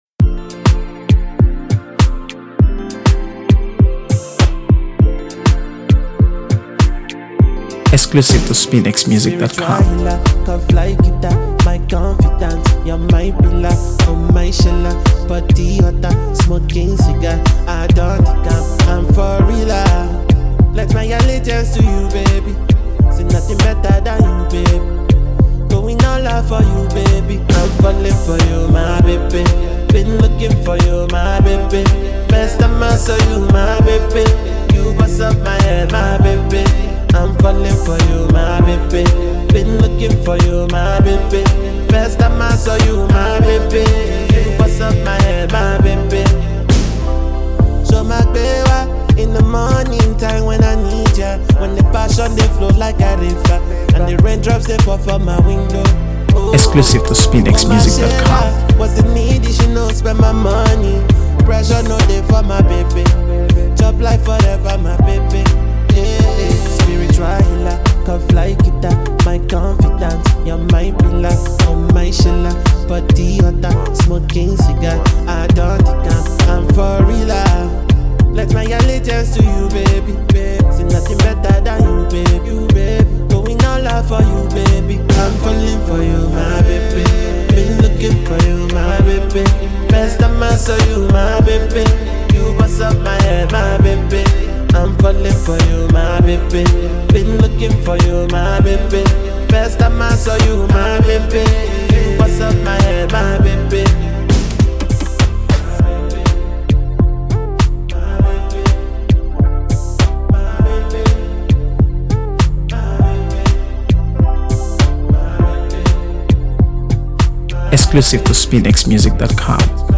AfroBeats | AfroBeats songs
a silky, melodic track
Effortlessly fusing rhythmic percussion with airy harmonies
creates a laid-back yet infectious vibe
tender, catchy, and undeniably replay-worthy.